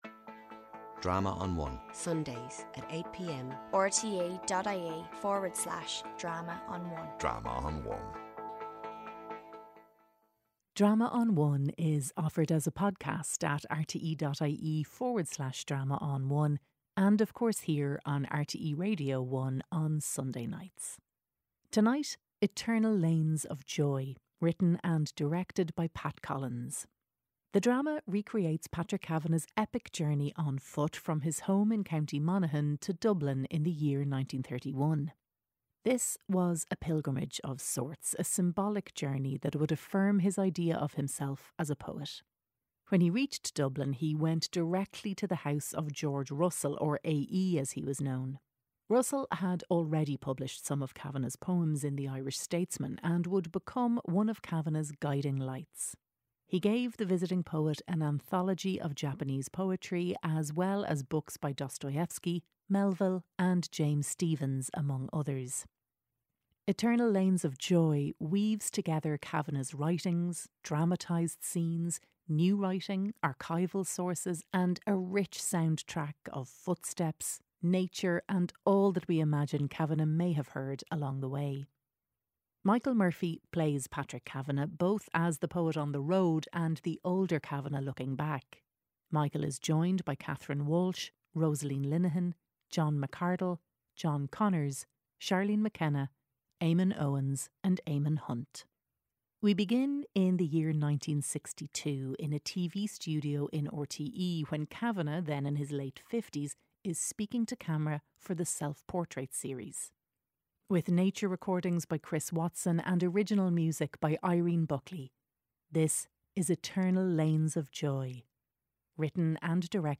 RTÉ Radio Drama's audio theatre department has for decades proudly brought audiences the very best dramatic writing and performances for radio from Ireland.